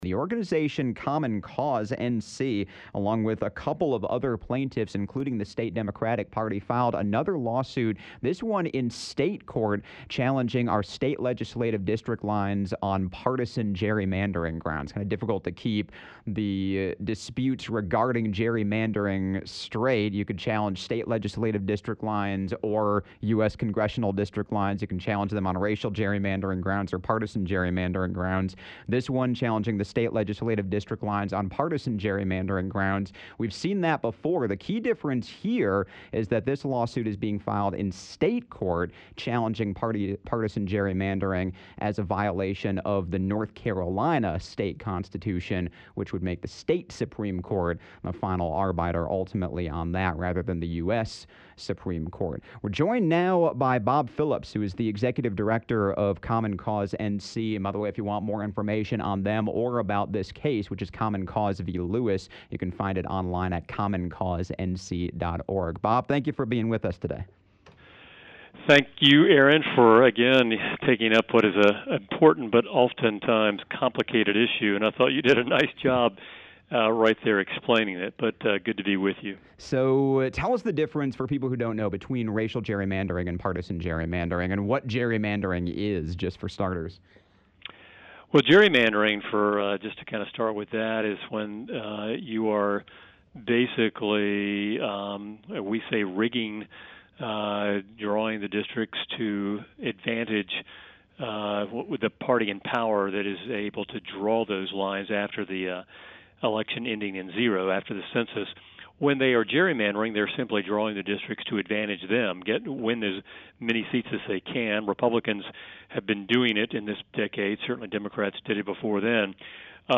On Air Today, Town Square